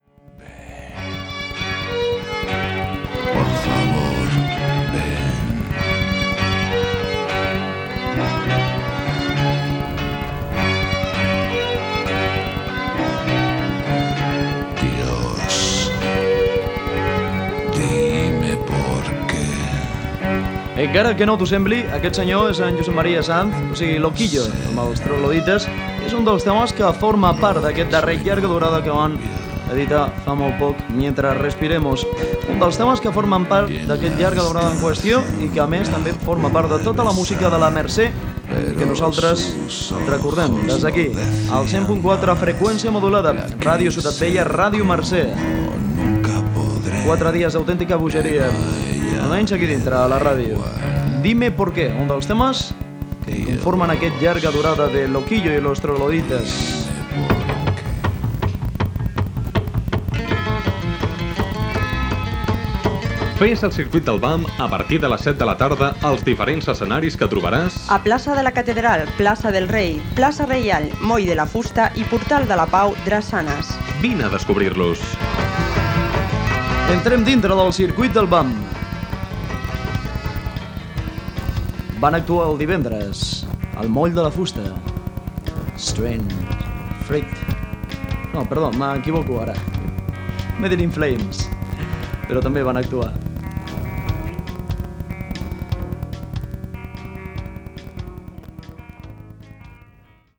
Identificació com Ràdio Mercè. Programa musical amb esment a la programació del Circuit del BAM de la Mercè 1994.
Musical
FM